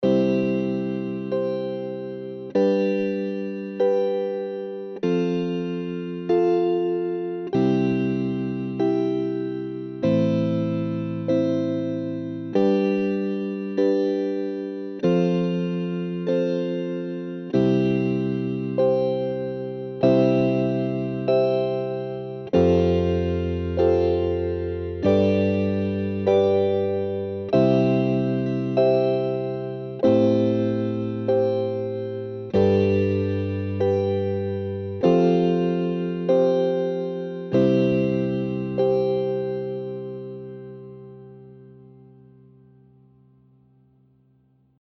Auch beim DUO Gold Star spielt das Instrument mit:
Im Pan-Modus erzeugt das Tremolo Bewegungen im Panorama: